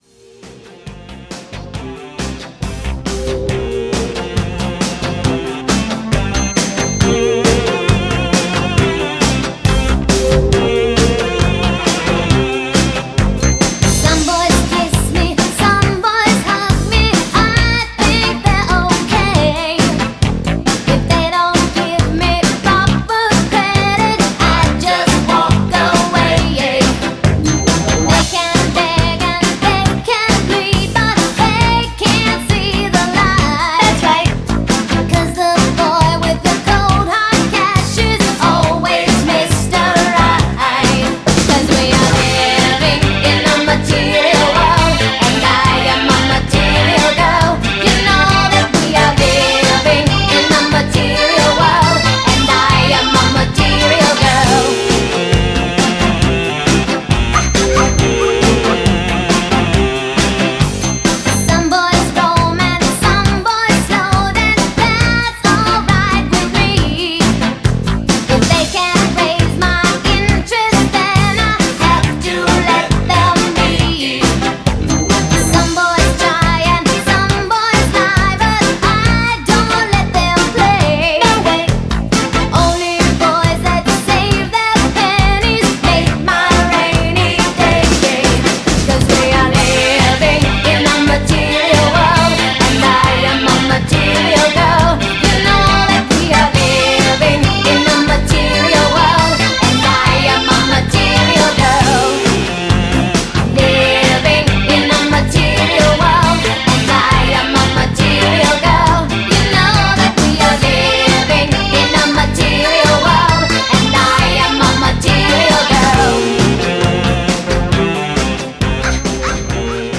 BPM135-138
Audio QualityMusic Cut